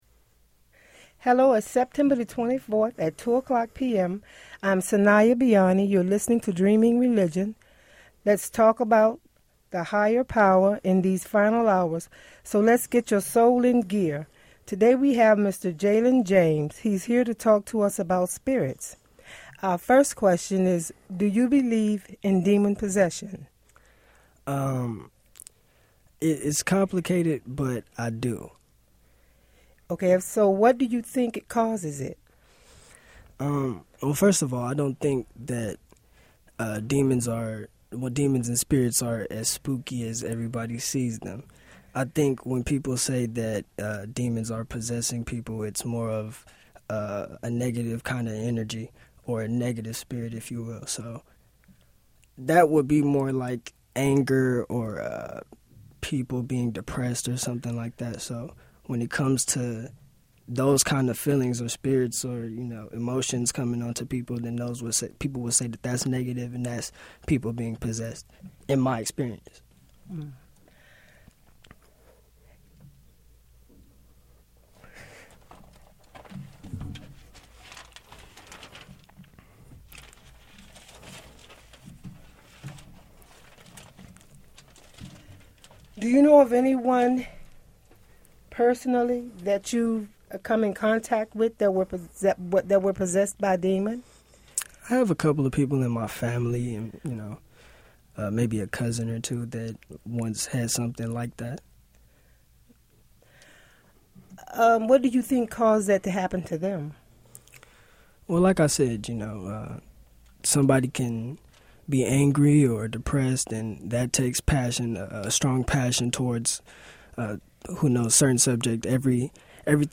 Dreaming Religion is a religious talk show